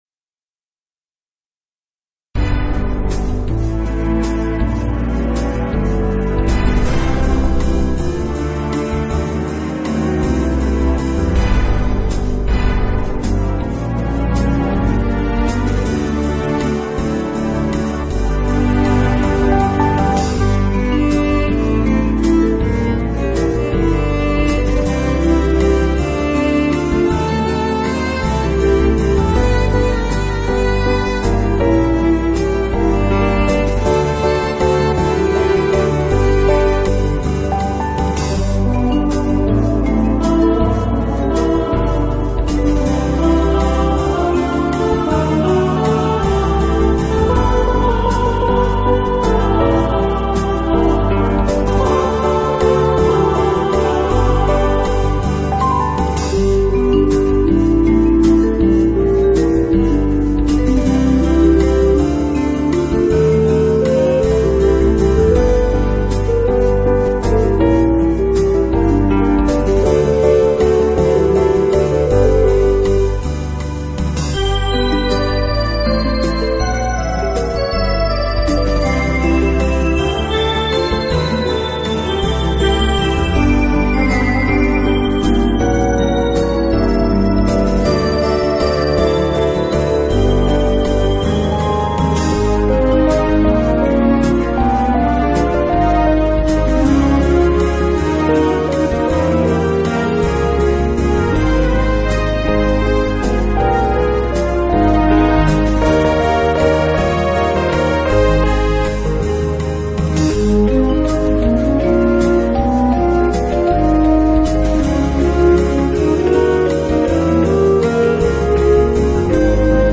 Songs acappella / barbershop style The four unison voices